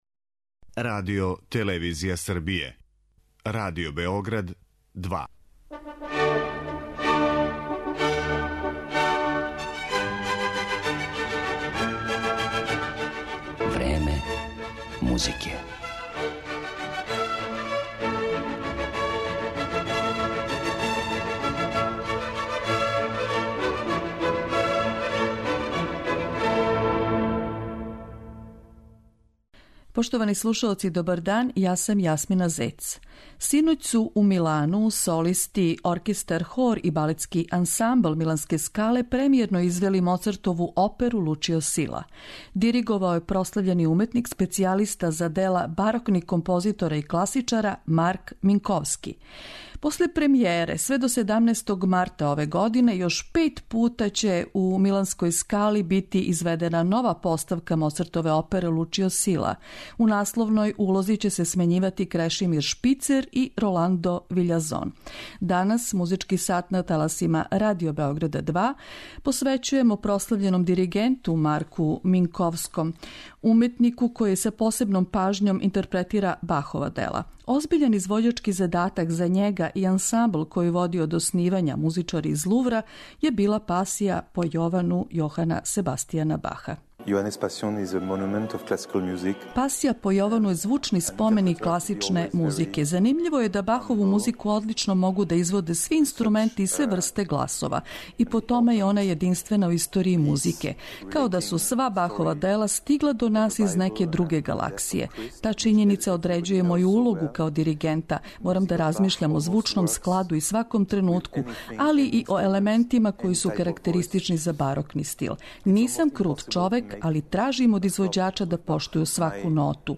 Маркo Минковски ужива углед специјалисте за извођење дела барокних композитора и класичара. Заједно са ансамблом "Музичари из Лувра", који води од оснивања, представићемо га као изврсног интерпретатора дела Јохана Себастијана Баха, Георга Фридриха Хендла, Марк- Антоана Шарпантјеа и Јозефа Хајдна.